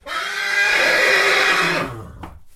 For interest, the neighing of a horse has been added below.
Horse-neigh-sound1.mp3